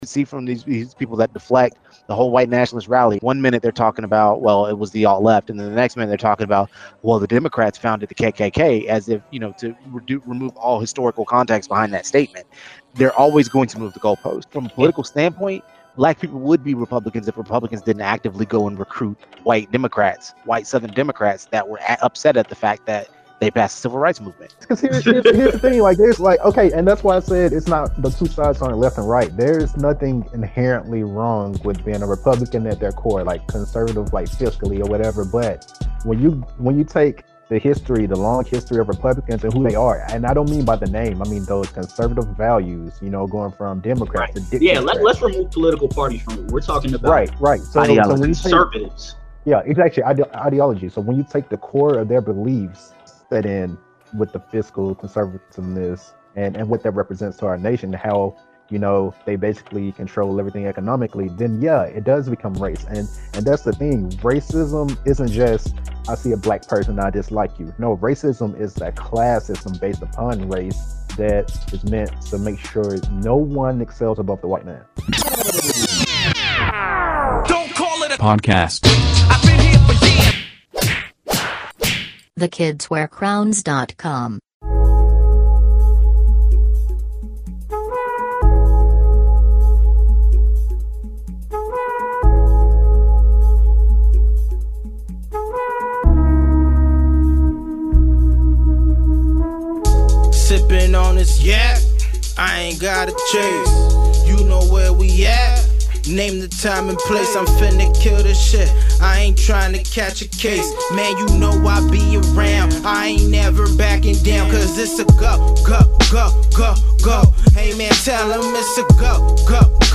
Usually we're really lighthearted on our podcasts, but this time we felt the need to be serious